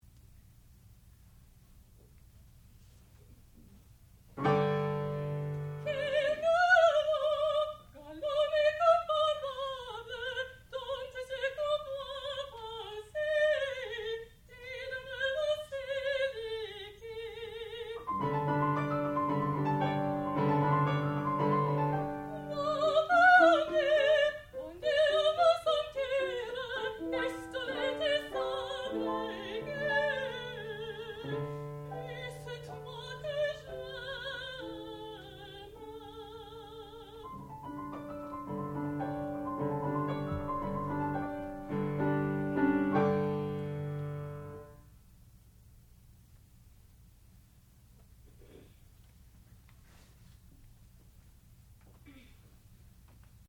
sound recording-musical
classical music
piano
Master's Recital
mezzo-soprano